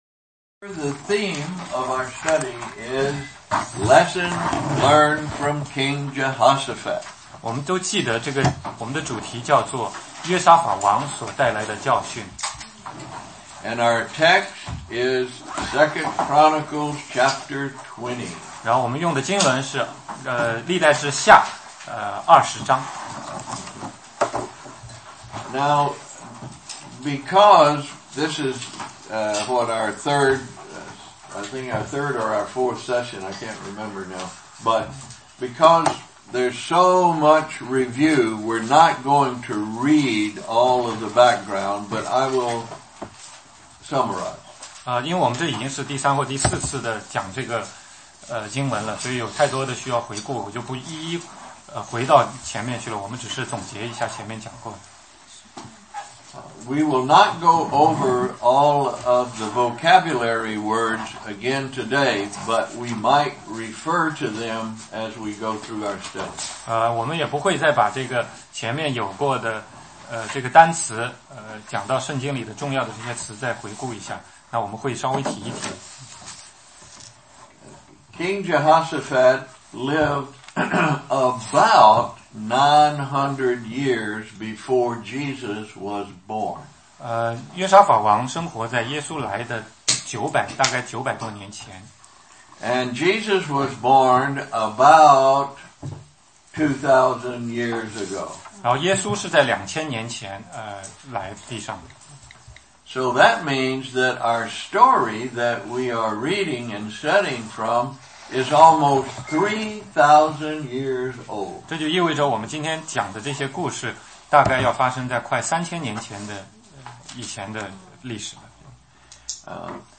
16街讲道录音 - 其它